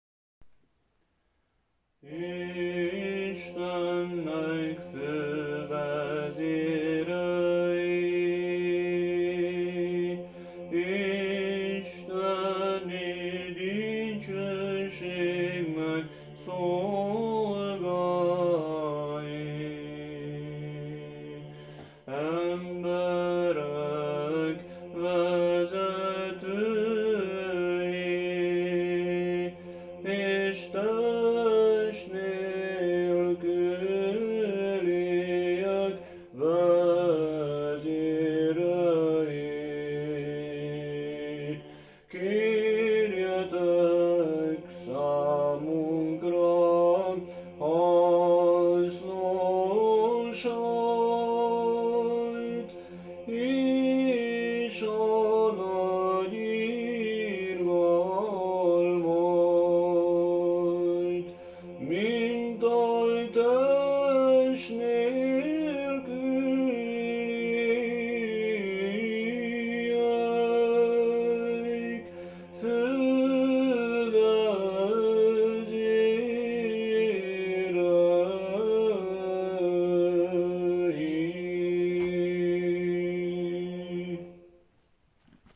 Vasárnapi délelőtti istentisztelet november 8-án, Szegedről
Kondákion, 2. hang